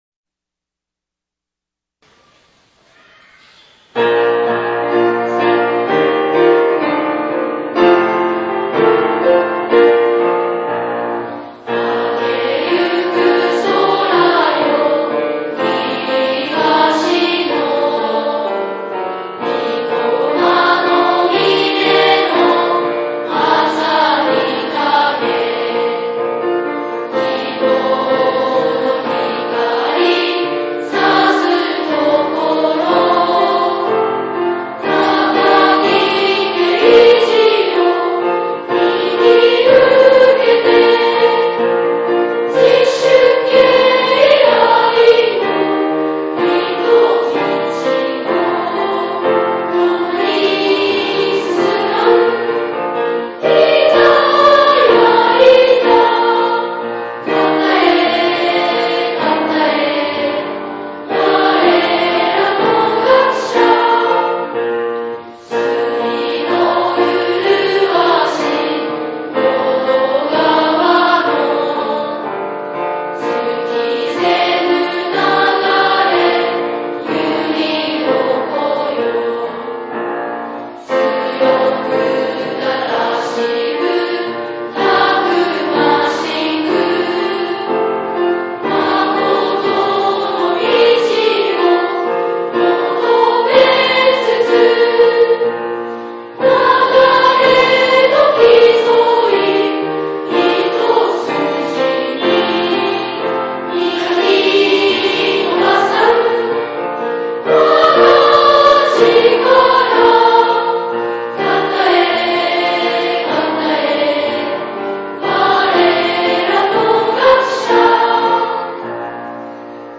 ⇒ 片江小学校 校歌(歌唱)